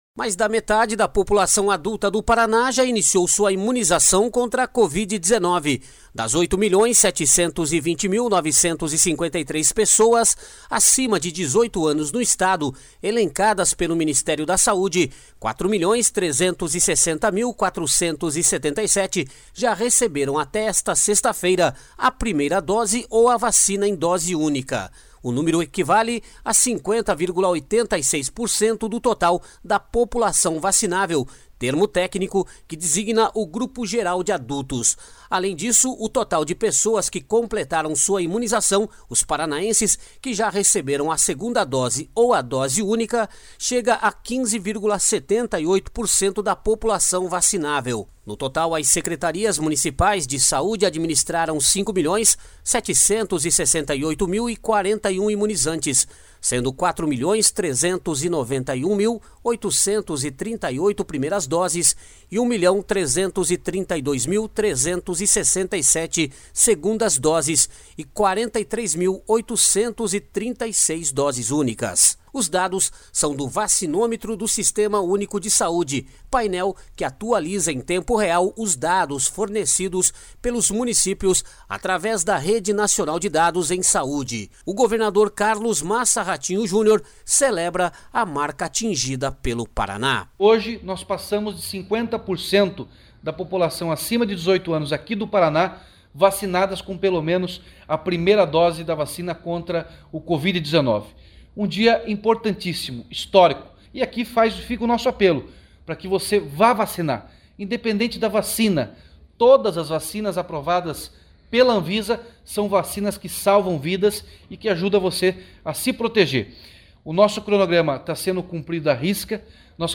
O governador Carlos Massa Ratinho Junior celebra a marca atingida pelo Paraná.// SONORA RATINHO JUNIOR.//
Segundo o secretário de estado da Saúde, Beto Preto, desde a semana passada, o Paraná trabalha com uma nova metodologia de distribuição de vacinas.// SONORA BETO PRETO.//